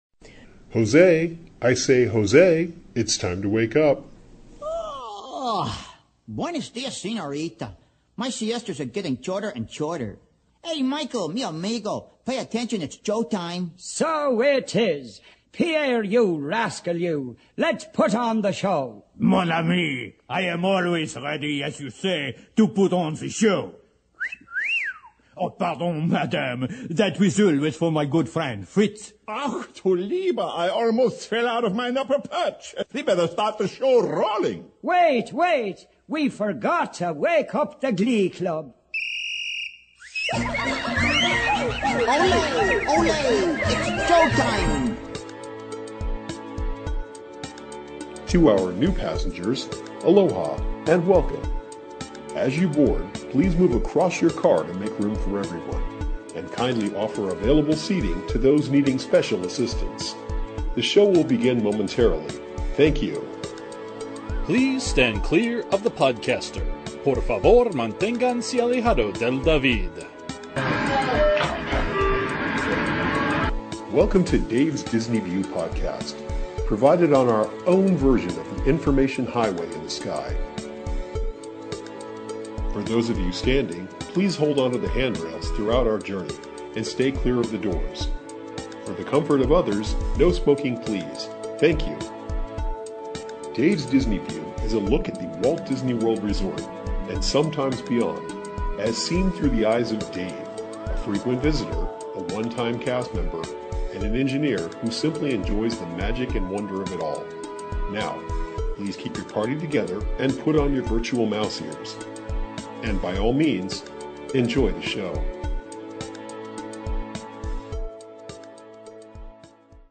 While I was in the Studios, I also dropped into One Man's Dream, rode Toy Story Midway Mania, and caught MuppetVision 3-D. I present an audio podcast that covers those three areas.